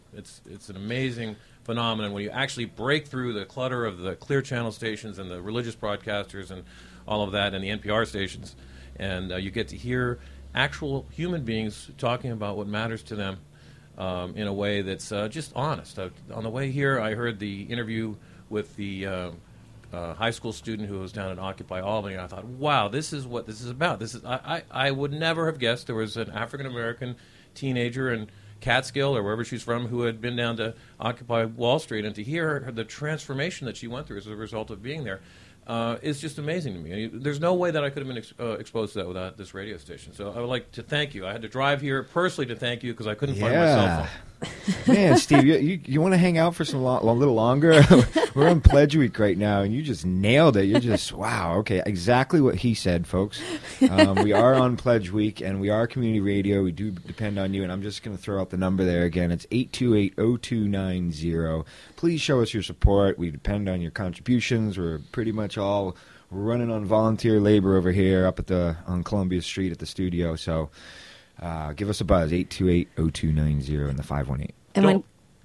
Recorded from "Tell It Like It Is" on WGXC.